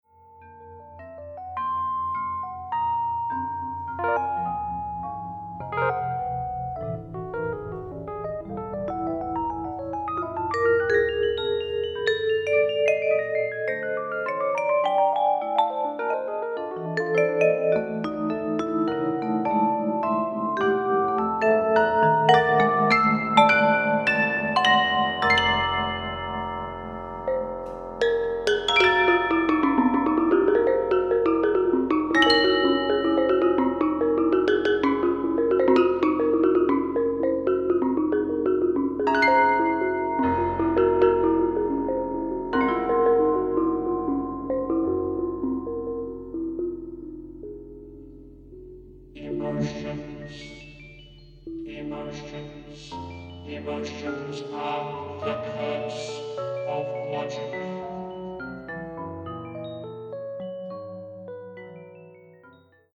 for keyboards and piano
spoken in a strange techno-insect voice